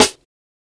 Heart Attack Snare.wav